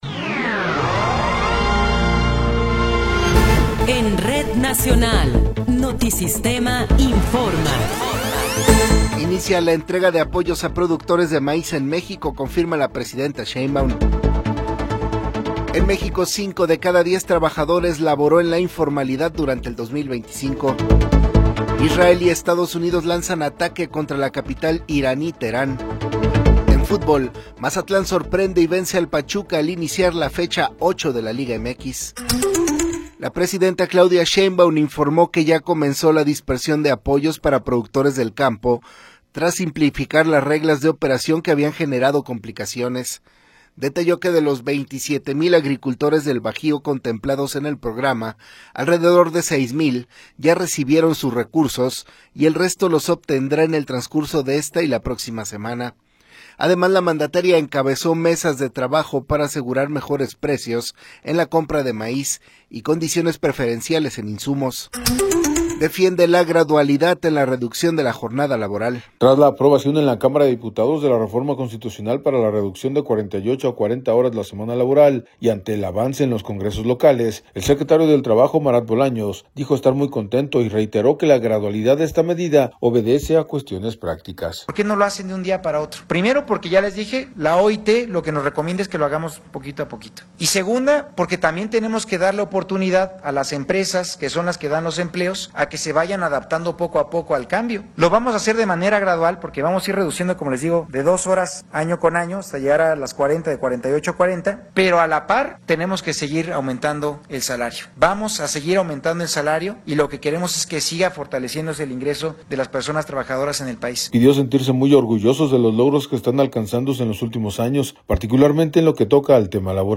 Noticiero 8 hrs. – 28 de Febrero de 2026
Resumen informativo Notisistema, la mejor y más completa información cada hora en la hora.